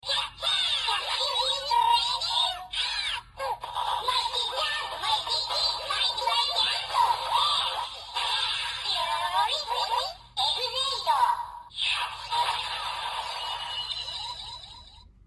Ea饱藏音效.MP3